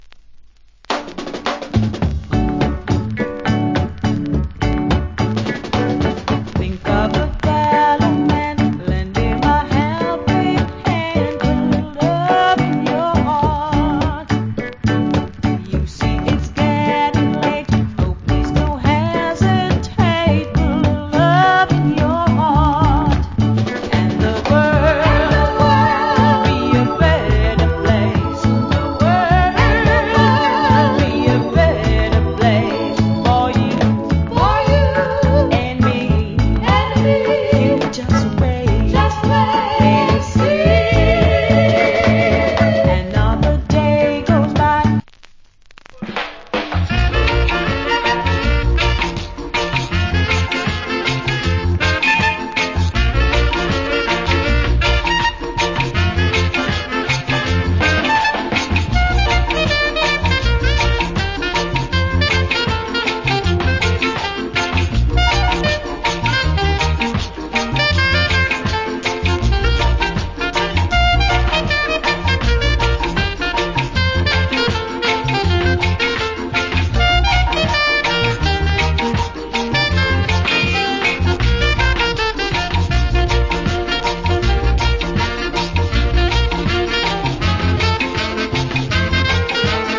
Great Duet Early Reggae Vocal. / Nice Early Reggae Inst.